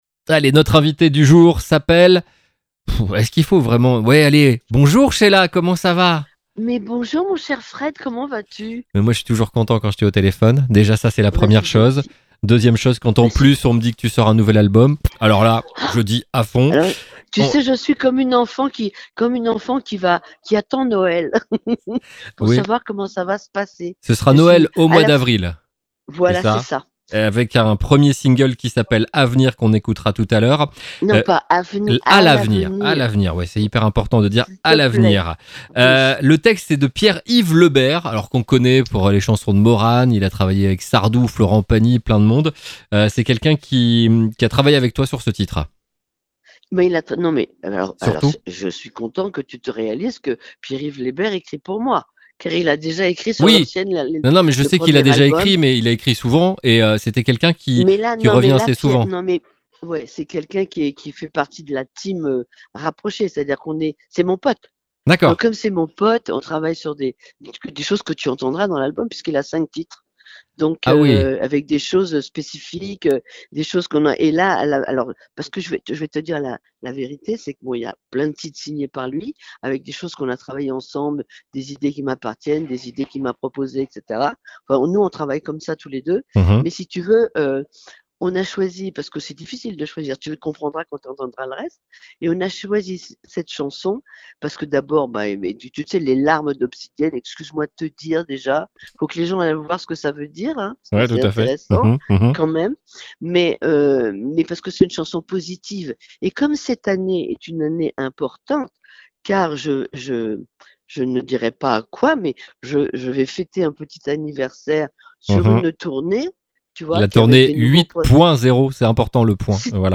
3. Les interviews exclusifs de RCB Radio